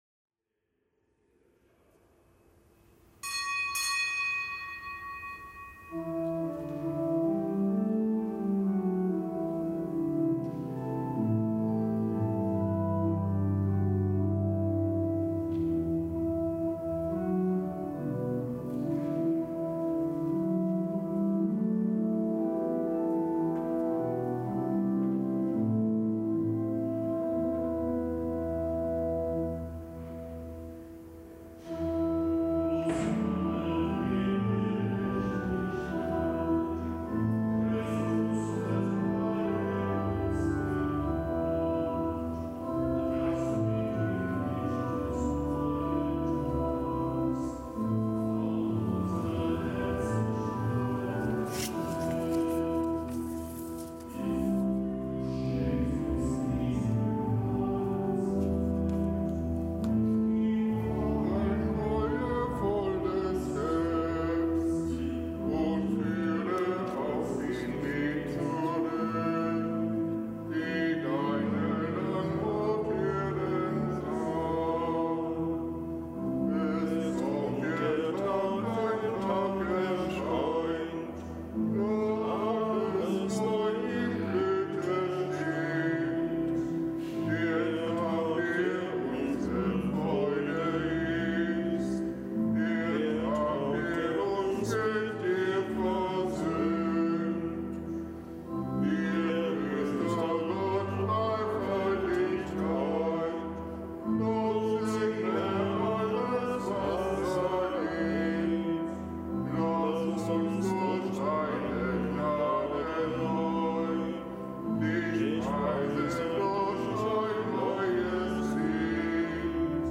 Kapitelsmesse aus dem Kölner Dom am Freitag nach Aschermittwoch.